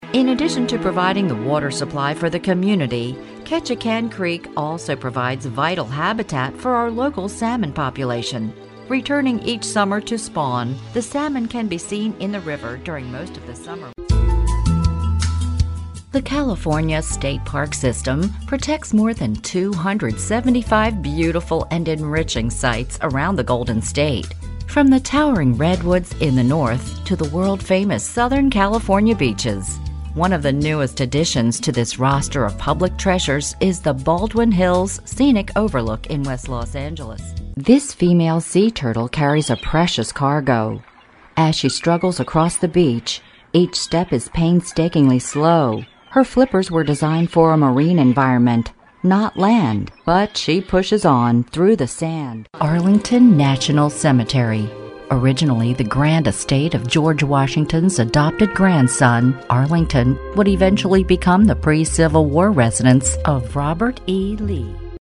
Sprechprobe: Industrie (Muttersprache):
Mid range voice with energy, conversational, even character voices